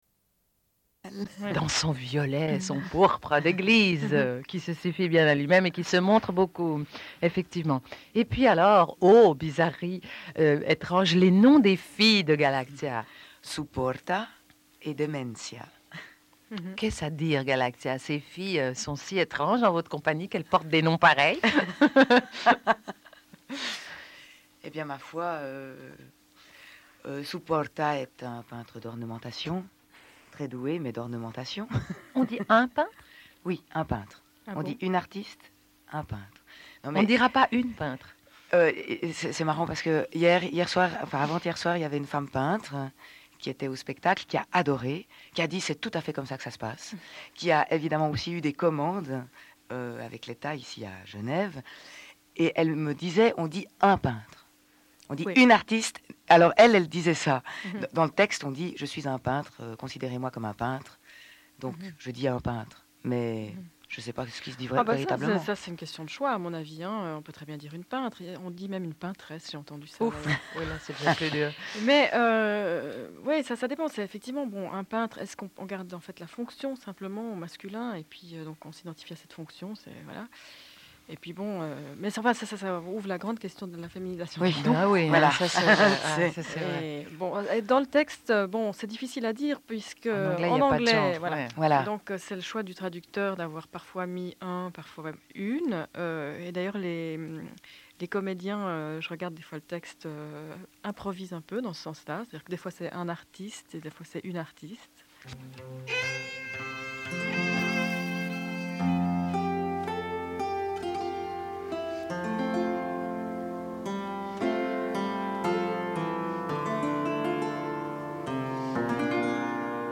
rediffusion d'un entretien
Une cassette audio, face B
Radio